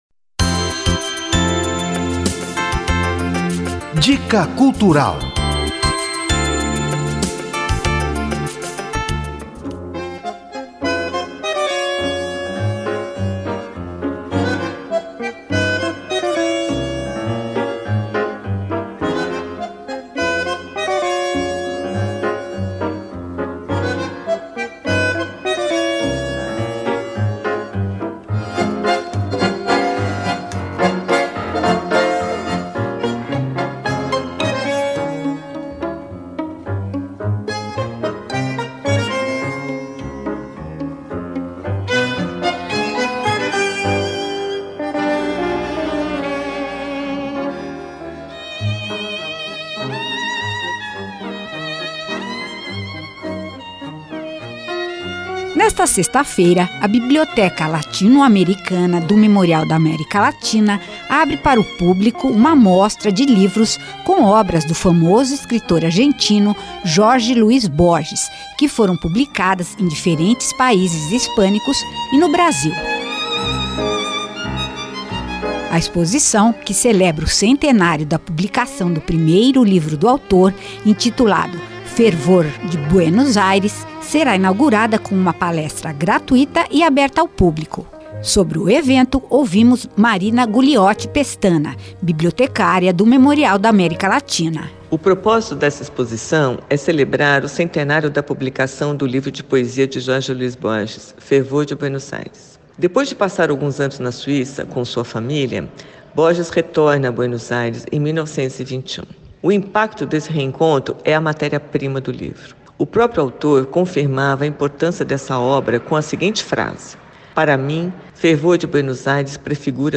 Confira a dica cultural veiculada pela Rádio USP.